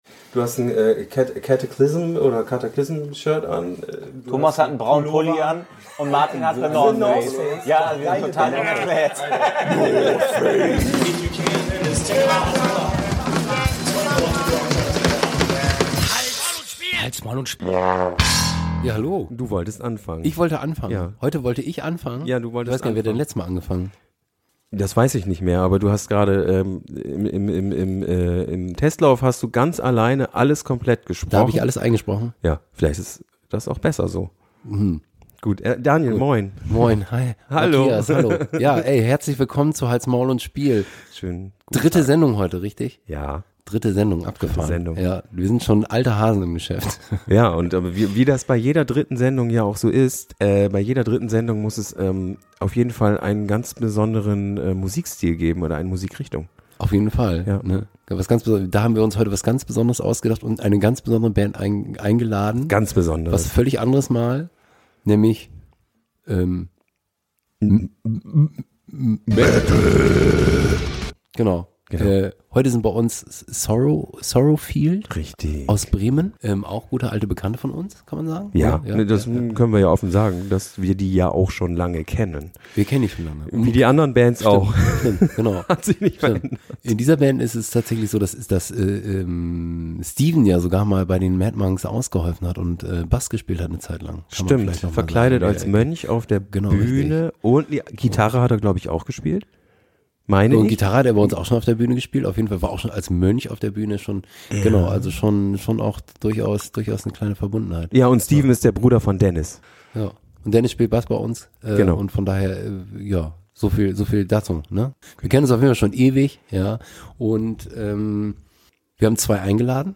Sound-Triggerwarnung : der Sound ist dieses Mal leider etwas gewöhnungsbedürftig. Falls es da zu Problemen bei der Verständlichkeit kommt, möchten wir uns dafür wirklich entschuldigen!
Live gespielt.